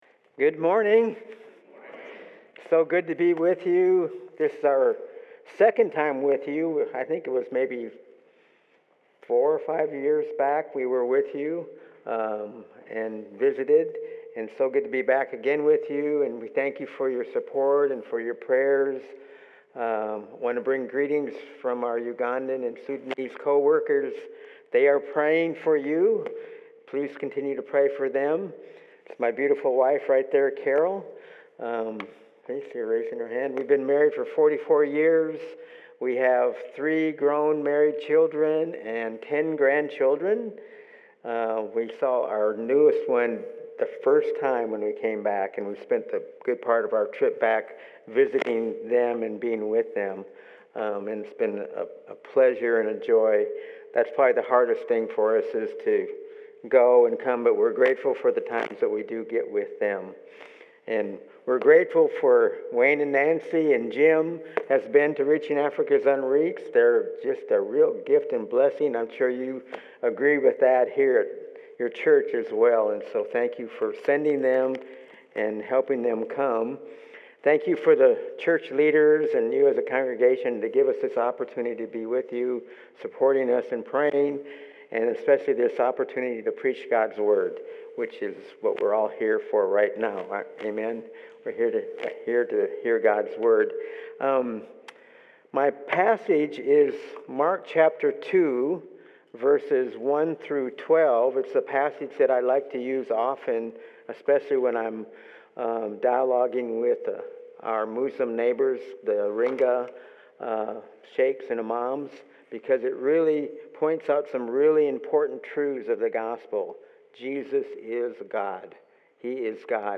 Sermons
Sermon audio from Faith Community Church in Prospect, KY.